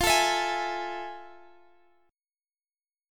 Listen to Fmaj7#9 strummed